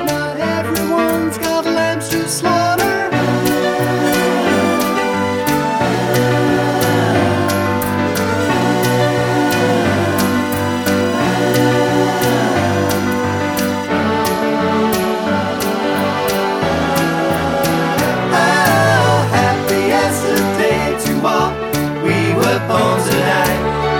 Without Intro Pop (2000s) 4:08 Buy £1.50